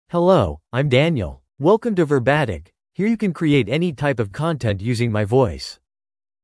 MaleEnglish (United States)
Daniel is a male AI voice for English (United States).
Voice sample
Male
Daniel delivers clear pronunciation with authentic United States English intonation, making your content sound professionally produced.